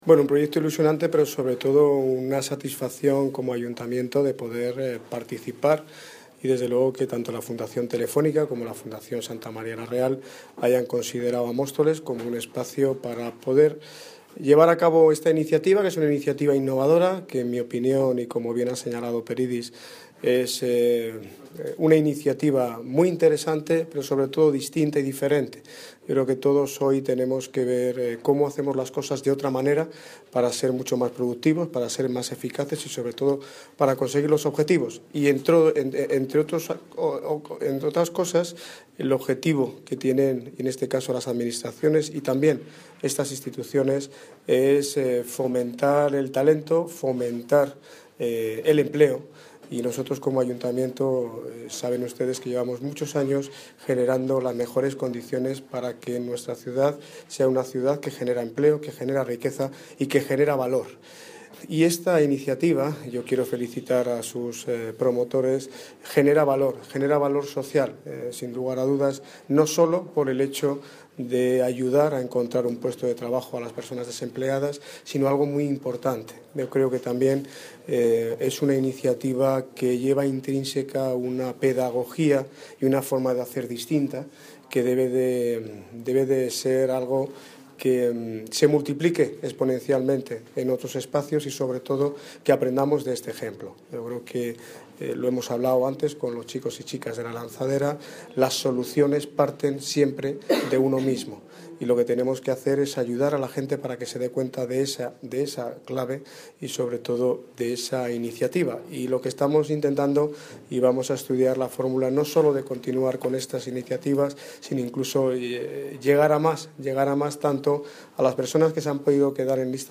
Audio - Daniel Ortiz (Alcalde de Móstoles) Sobre Lanzaderas de Empleo